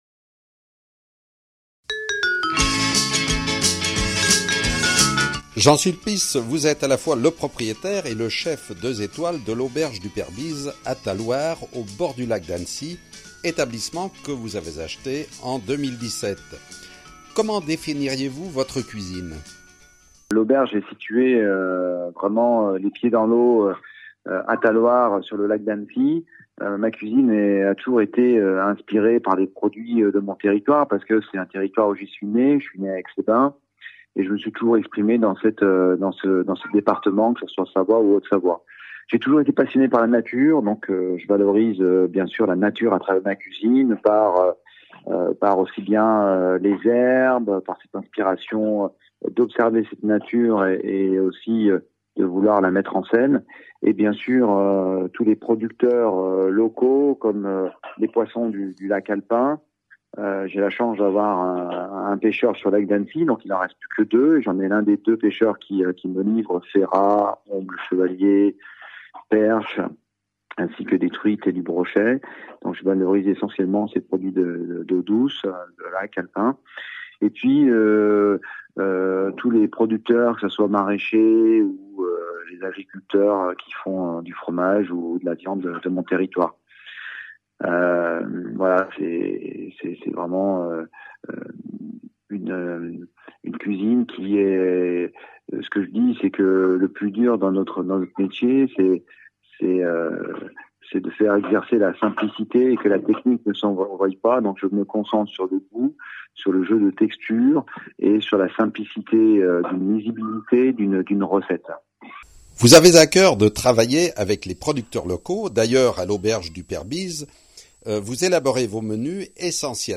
Contacté par téléphone, il nous explique le sens de cette démarche collective et dévoile sa passion pour la cuisine et les produits du terroir.